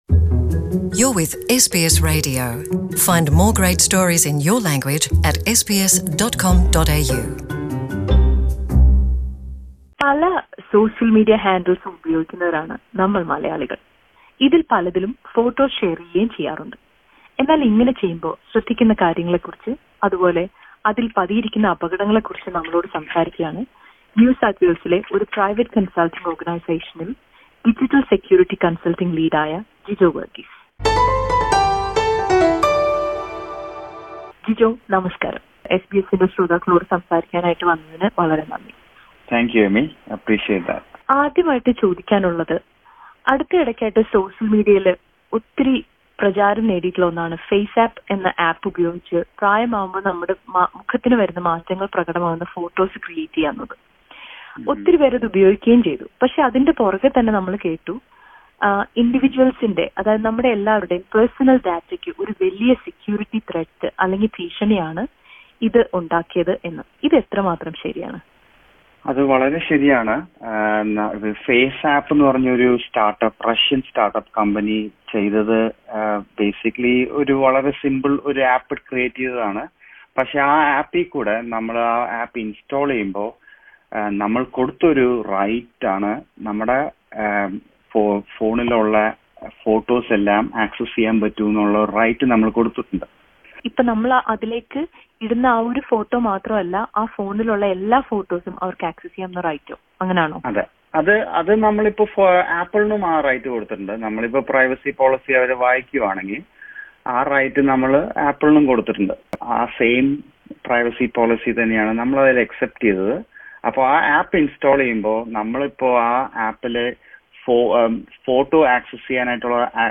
The dangers around posting your photos on various digital platforms is always a point of discussion. With identity theft and crimes associated with on the rise SBS Malayalam speaks to an expert in the area.